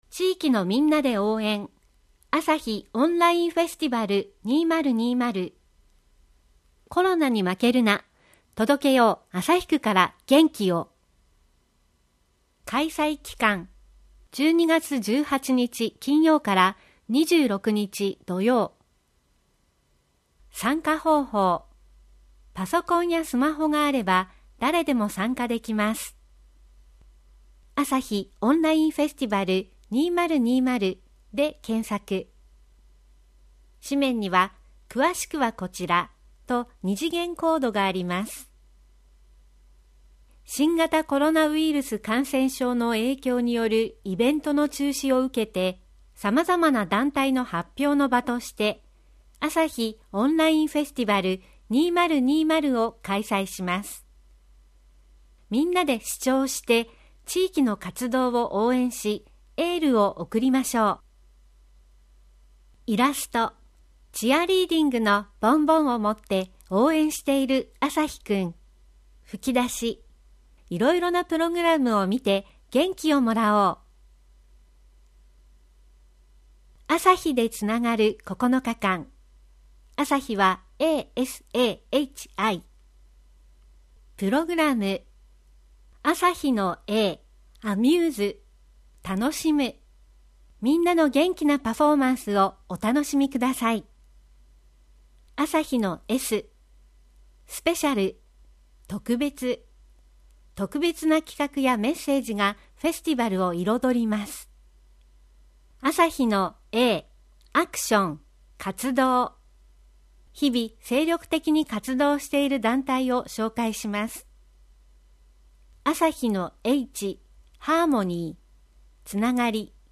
음성판